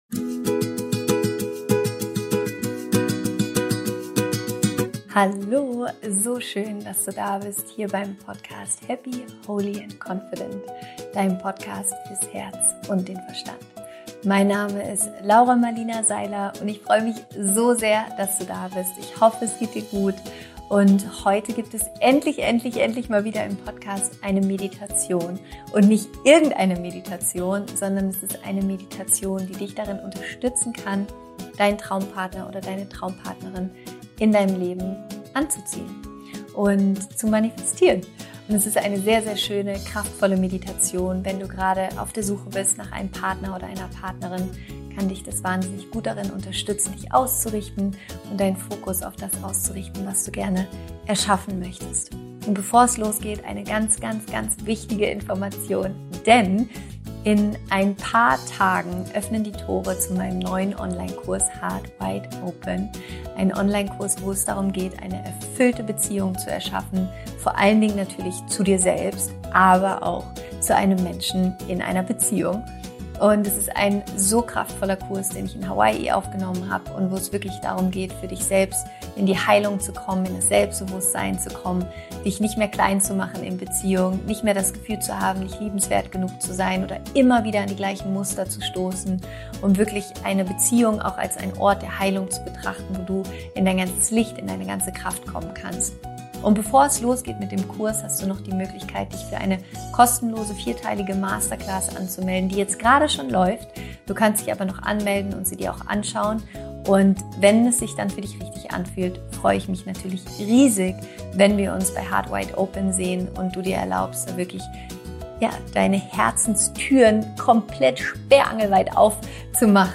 Darum teile ich mit dir in der neuen Podcastfolge eine wunderschöne, kraftvolle Meditation zu diesem Thema. Diese Meditation kann dich darin unterstützen, deine*n Traumpartner*in anzuziehen und zu manifestieren. Sie hilft dir dabei, deinen Fokus auf das auszurichten, was du gerne erschaffen möchtest und alte Mauern um dein Herz fallen zu lassen.